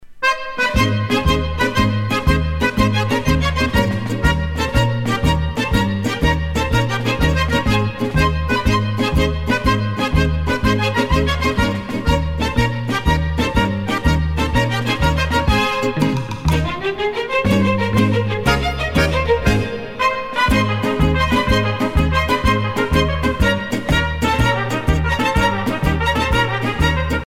danse : tarentelle
Pièce musicale éditée